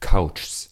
Ääntäminen